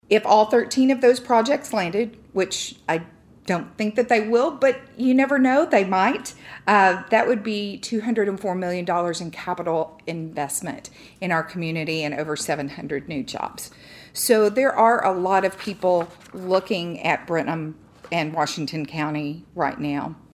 At Thursday’s meeting of the Brenham City Council